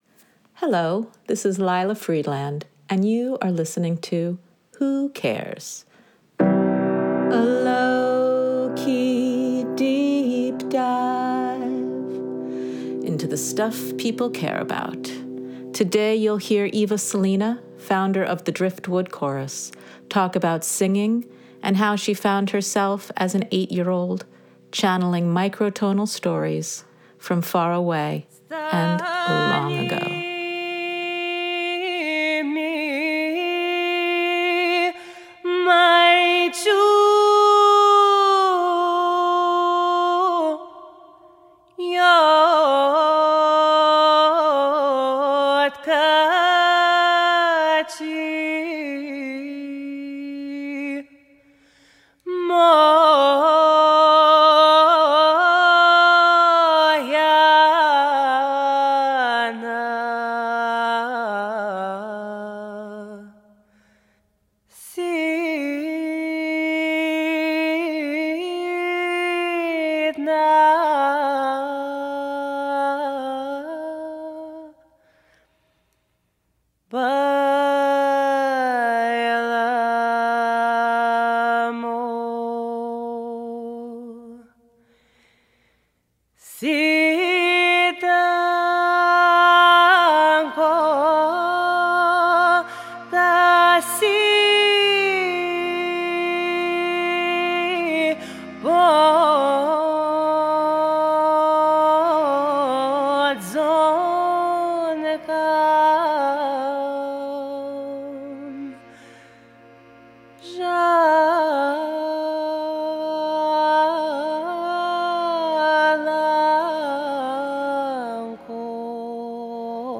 Hosted by various prospective WGXC volunteer progr...